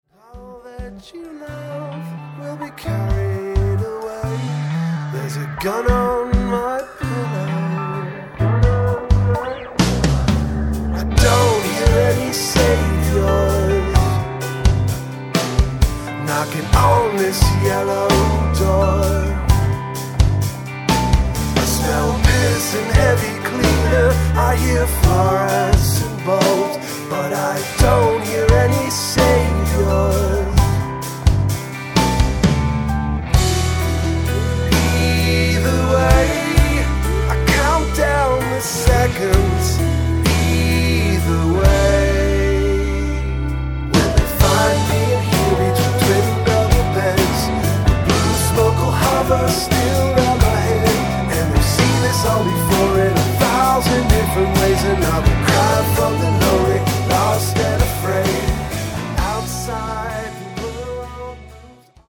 keyboards, backing vocals
guitars, lead and backing vocals
drums, percussion, backing vocals
bass, backing vocals